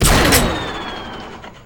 pshoot2.ogg